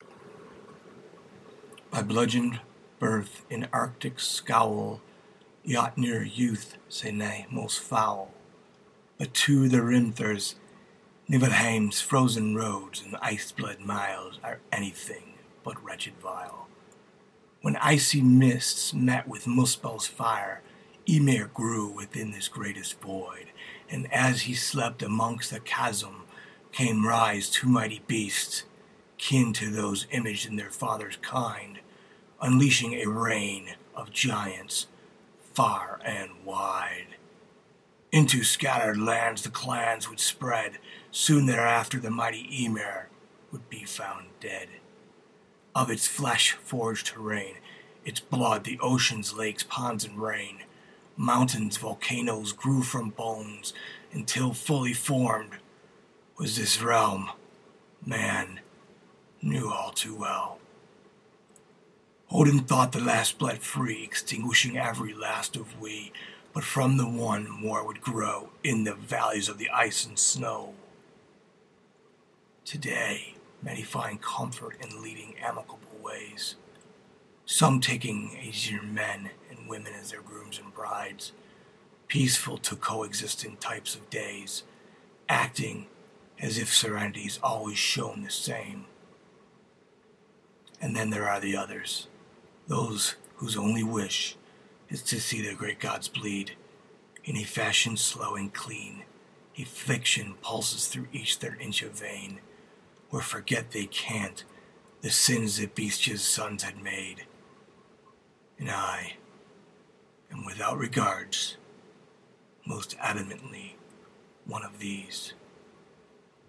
Poetry, Reading